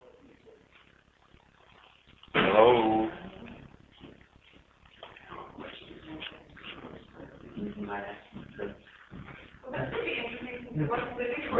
In theory, it's the recording of ghost voices.
Then at 5 seconds you hear two words that we can't identify as any of us, and it's just not something we'd be likely to say. Everything after those two words is us talking.